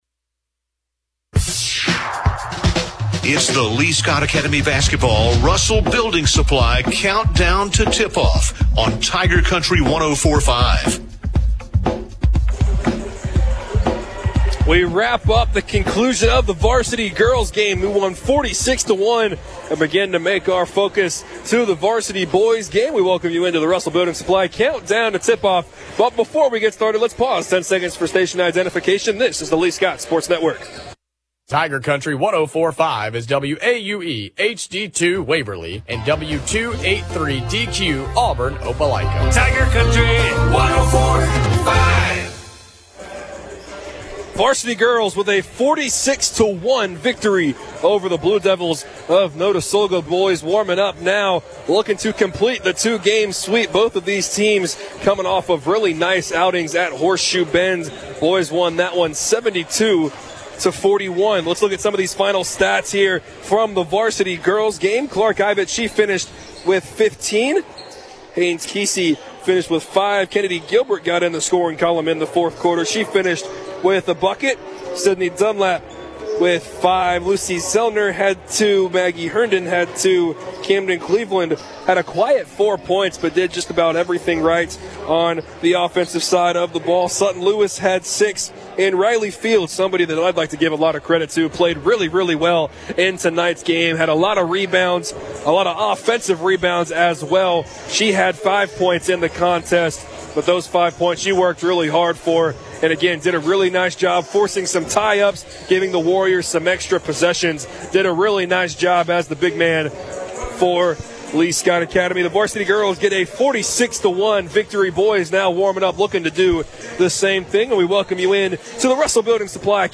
Dec. 5, 2024 - Listen as the Lee-Scott Warriors host Notasulga. The Warriors won 71-36.